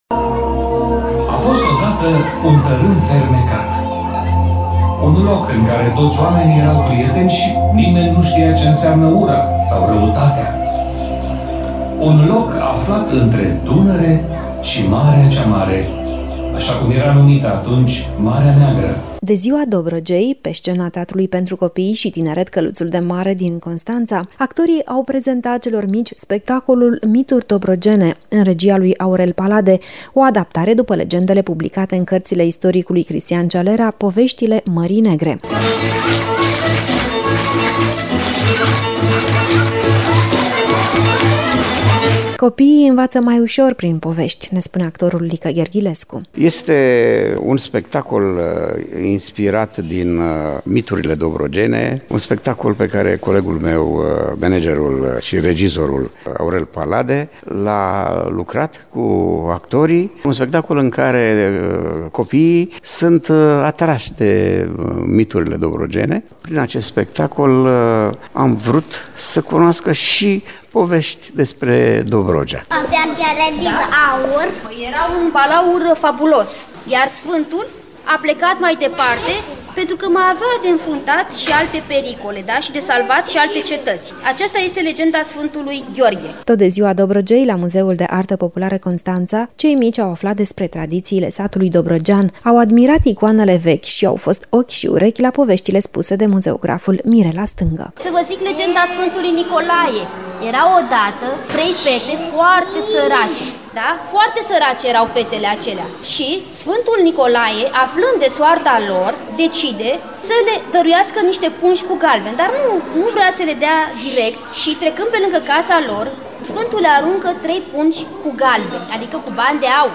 în reportajul următor.